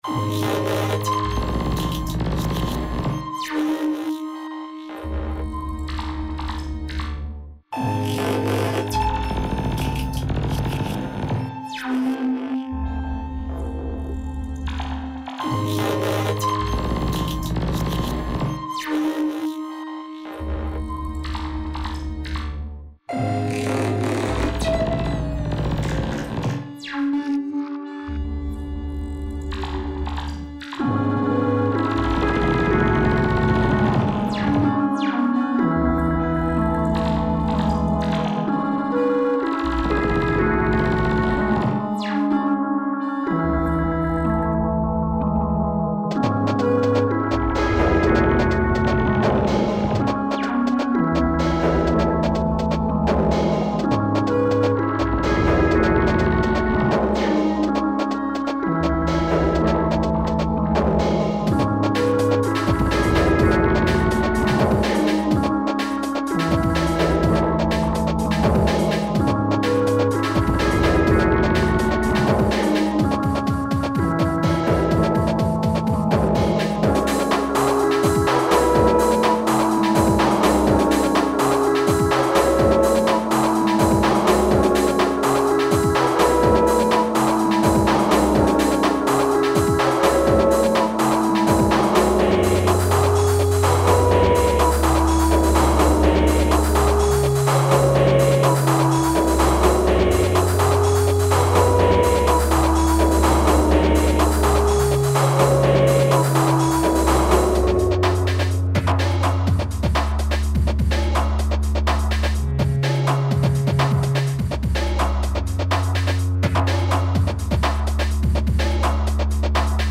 experimental ambient